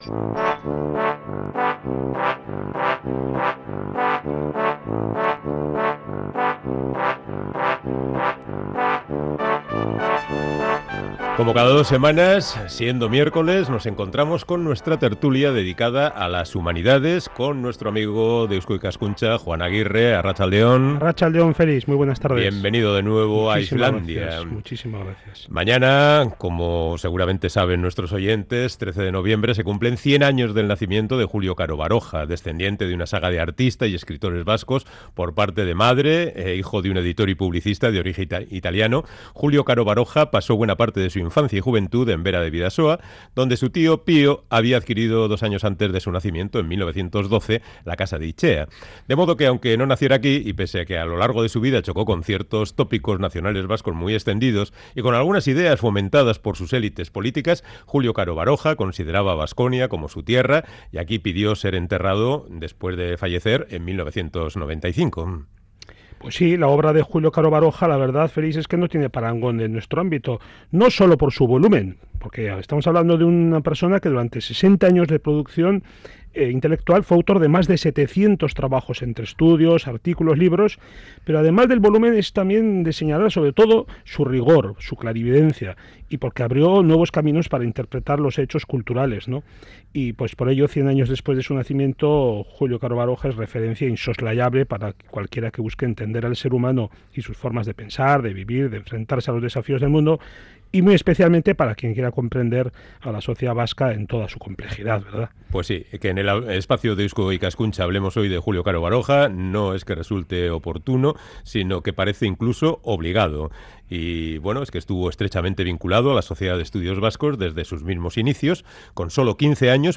Hemos hablado con él en la tertulia de humanidades de Iflandia de la figura del gran antropólogo Julio Caro Baroja, del que se ha conmemorado el centenario de su nacimiento.